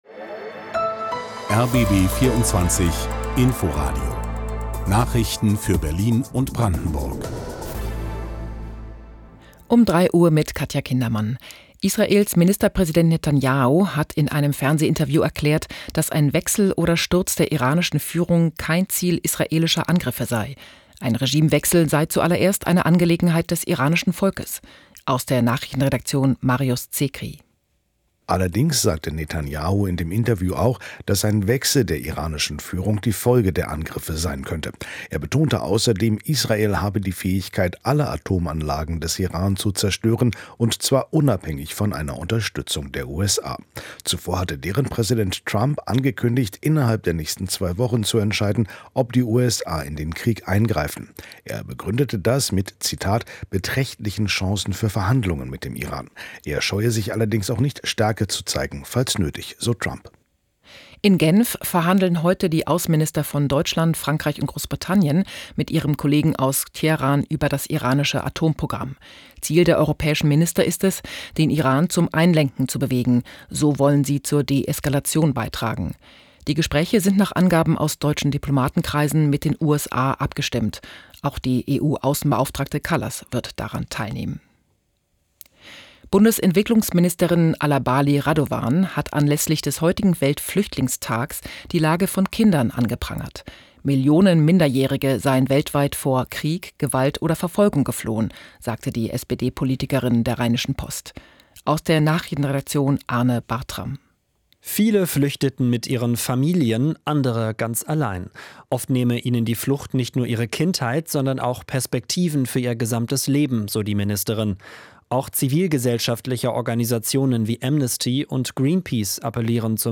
Nachrichten